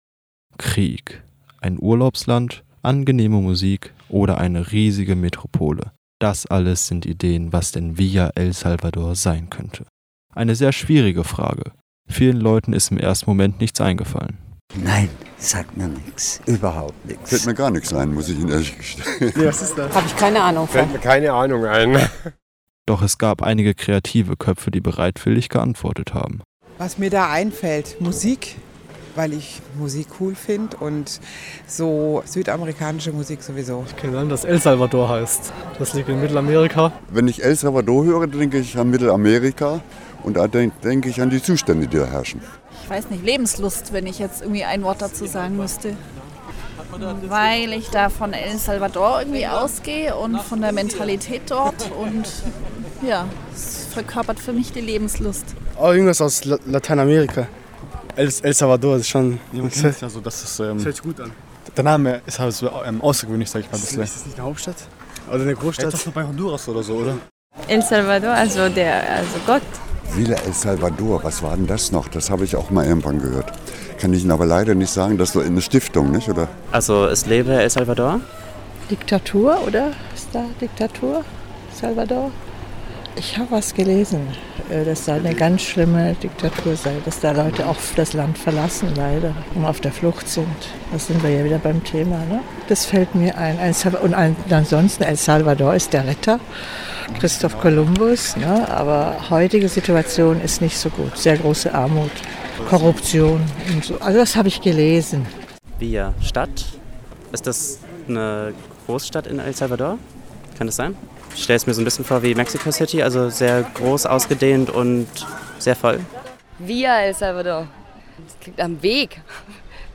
Im folgenden Beitrag sind die Antworten zu hören.
70320_Umfrage_zu_Villa_el_Salvador.mp3